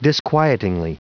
Prononciation du mot disquietingly en anglais (fichier audio)
Prononciation du mot : disquietingly